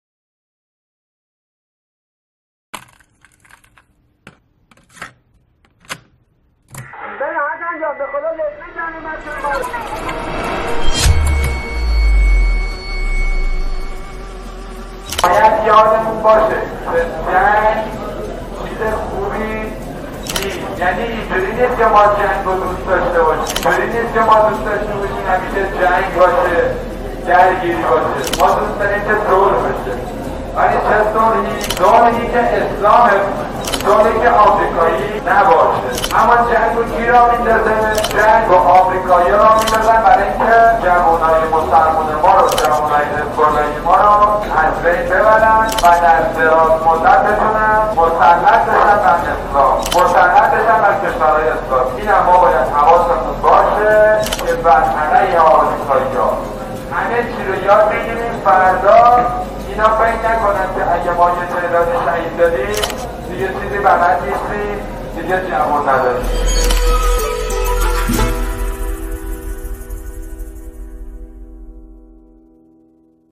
شهید باقری در ۲۵ شهریورماه سال ۱۳۶۱ در جمع رزمندگان اسلام در بحبوحه عملیات رمضان در قرارگاه کربلا درباره ماهیت صلح مدنظر انقلاب اسلامی سخن به میان آورد.